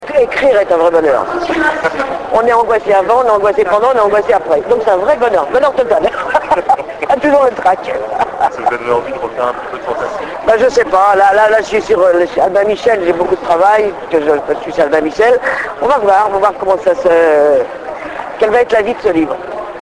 Interview Maud Tabachnik - Mai 2007